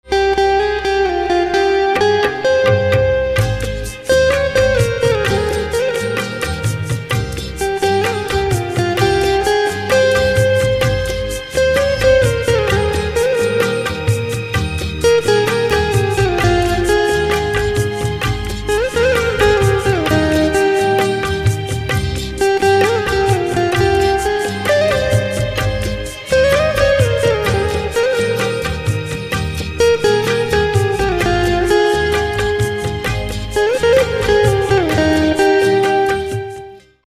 1. Romantic Bollywood Instrumental Ringtone